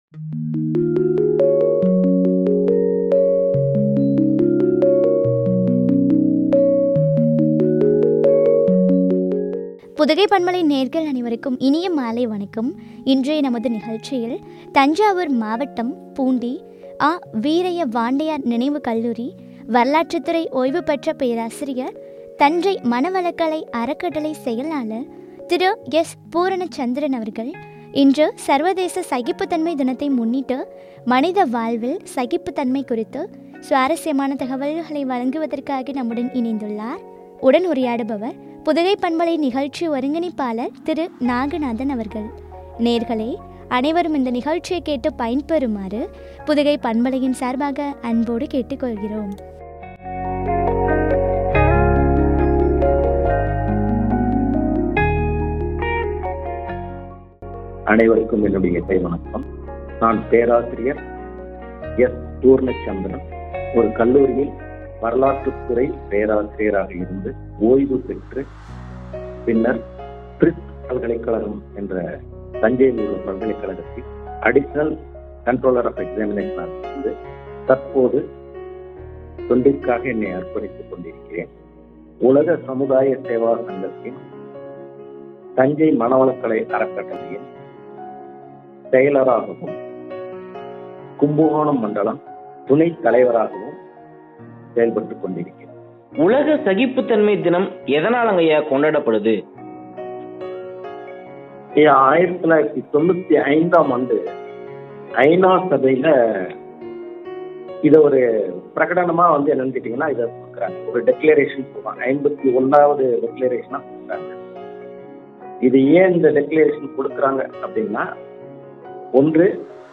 ” மனித வாழ்வில் சகிப்புத்தன்மை” குறித்து வழங்கிய உரையாடல்.